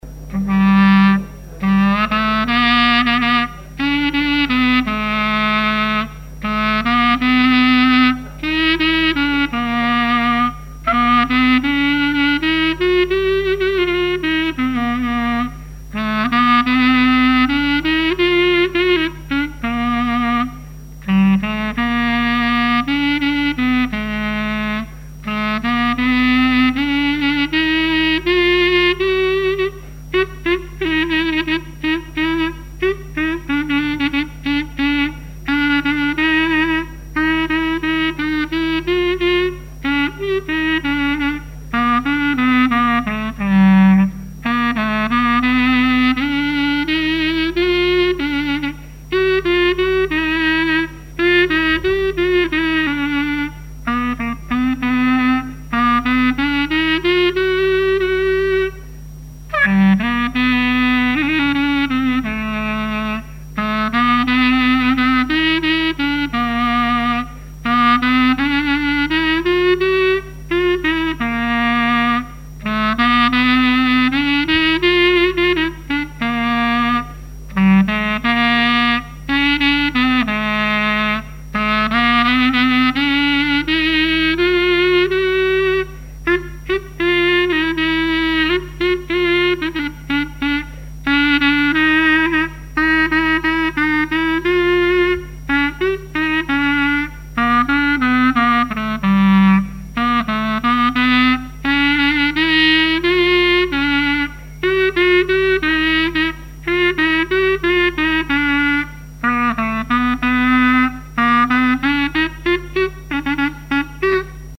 circonstance : fiançaille, noce
collectif de musiciens pour une animation à Sigournais
Pièce musicale inédite